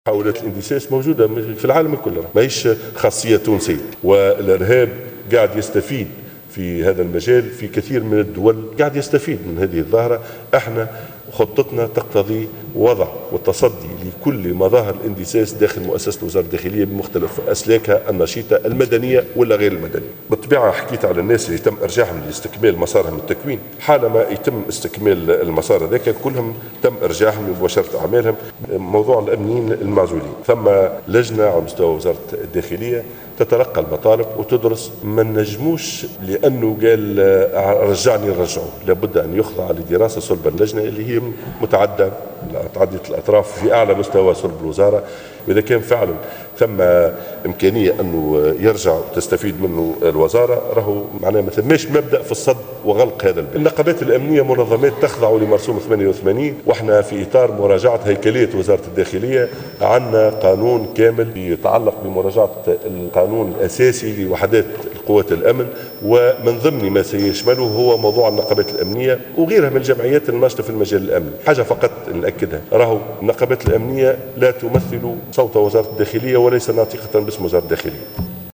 قال وزير الداخلية اليوم السبت في جلسة عامة بمجلس نواب الشعب أن النقابات الأمنية لا تمثل صوت الداخلية وليست ناطقة باسم للوزارة، بحسب تعبيره.